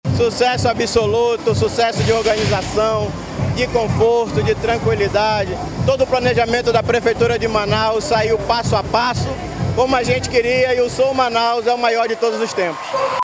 SONORA-OSVALDO-CARDOSO-Presidente-da-Manauscult.mp3